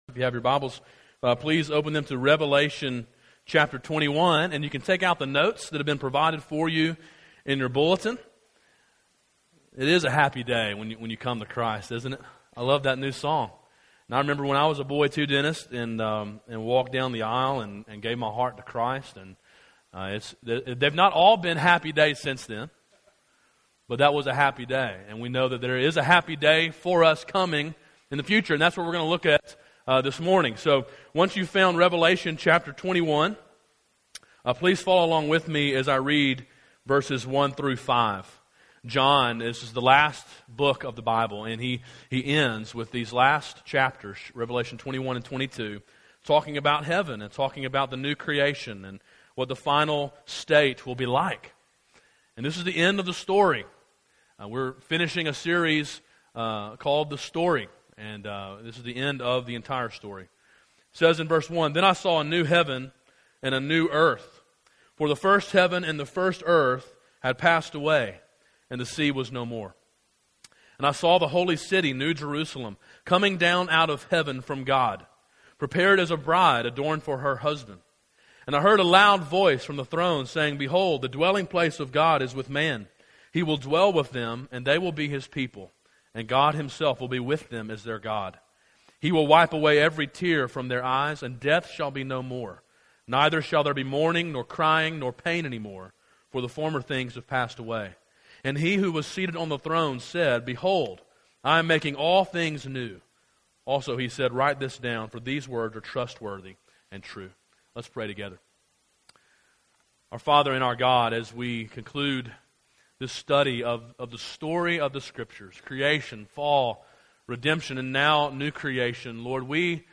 A sermon in a series titled The Story.